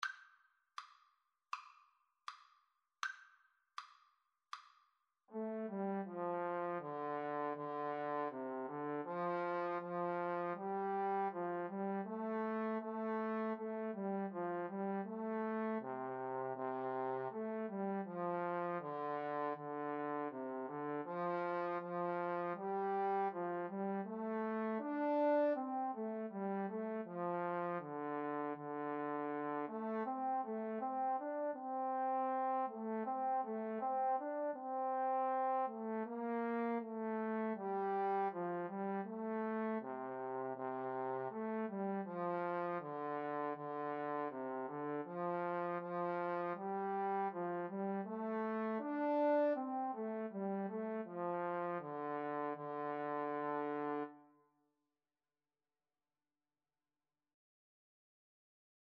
4/4 (View more 4/4 Music)
Andante Espressivo = c. 80
Trombone Duet  (View more Intermediate Trombone Duet Music)